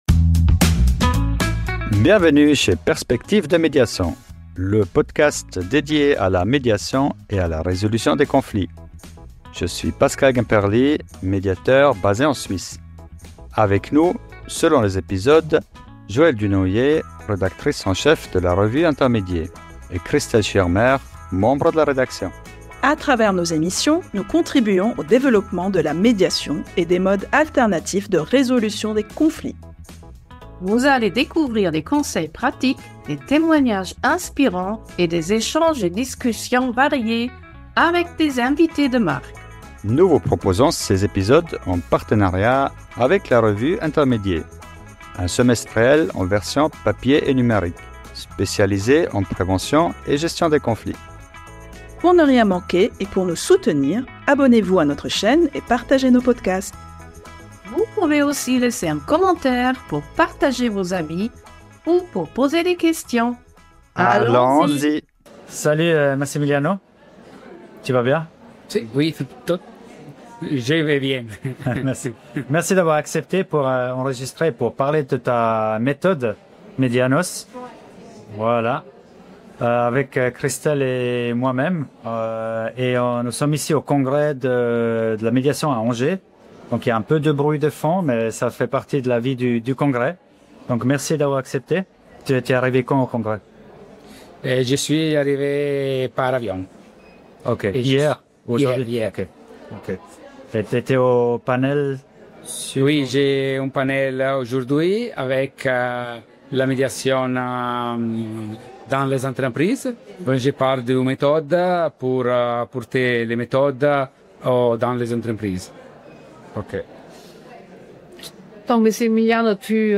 🎙 Bienvenue dans cet épisode de Perspectives de Médiation ! Dans cette émission enregistrée en direct du Congrès de la Médiation à Angers